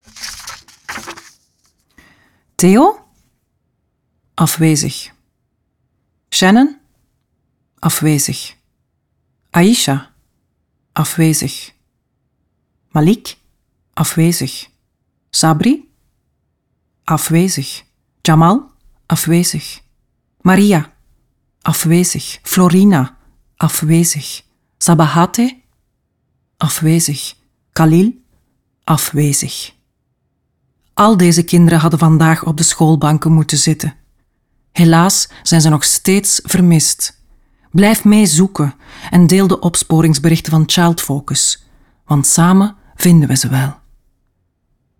Le bronze a été remporté par la Fondation belge Child Focus qui traite les disparitions et l’exploitation sexuelle des enfants. Le spot met en scène l’appel des élèves dans une classe d’école, mais tous sont absents.